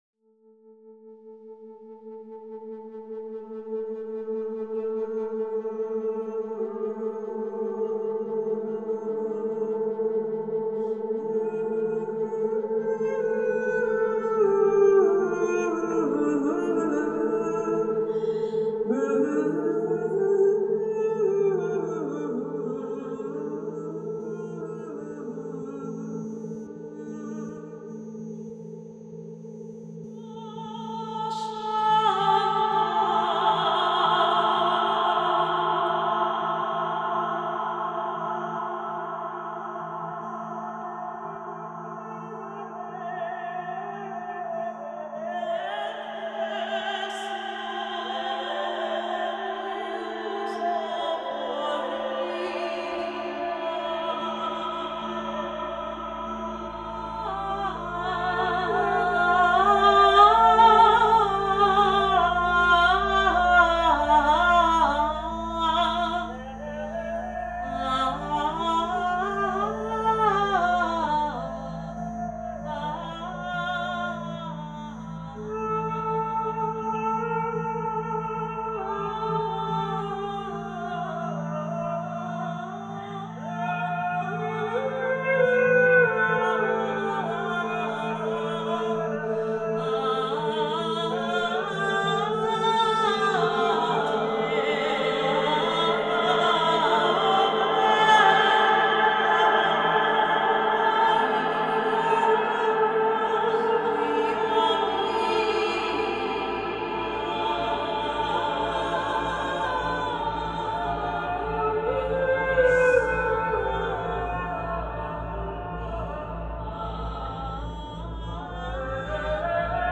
d’interpréter sa chanson de plusieurs manières différentes